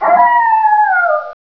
wolf3118.wav